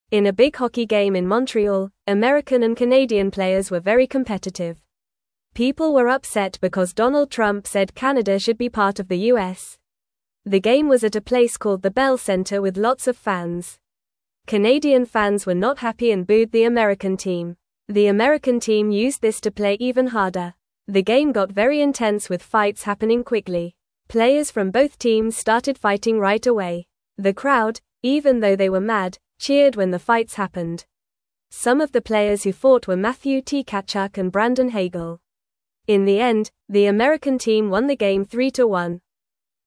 Fast
English-Newsroom-Beginner-FAST-Reading-Hockey-Game-with-Fights-and-Excited-Fans.mp3